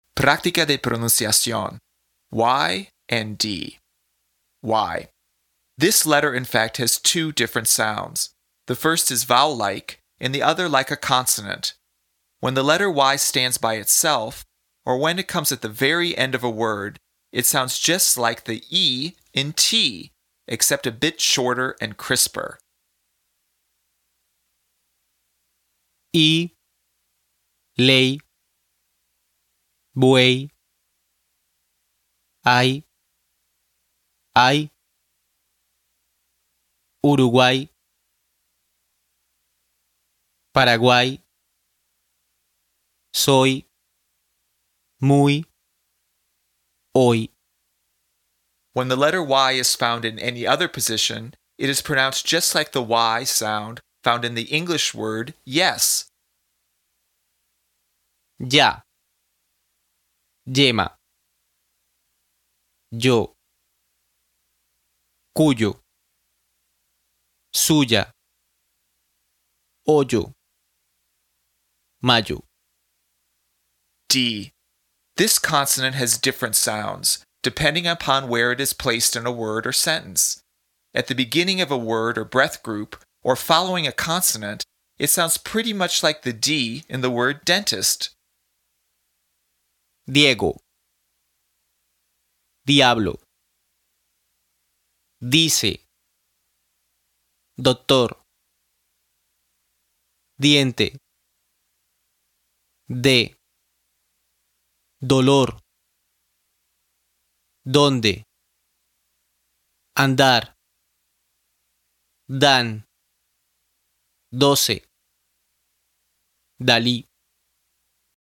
PRÁCTICA DE PRONUNCIACIÓN
When the letter “y” stands by itself or when it comes at the very end of a word, it sounds just like the “ee” in “tee,” except a bit shorter and crisper.
When the letter “y” is found in any other position, it is pronounced just like the “y” sound found in the English word “yes.”
At the beginning of a word or breath group, or following a consonant, it sounds pretty much like the “d” in the word “dentist.”